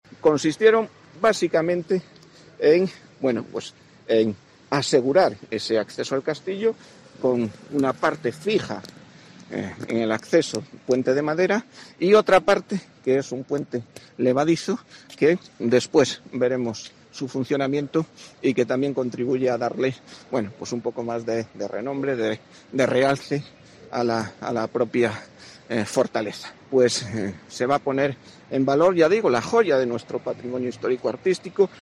La intervención ha sido realizada por la empresa especializa Trycsa y con una inversión de 65.881 euros. Escucha aquí las palabras del alcalde de Ponferrada, Olegario Ramón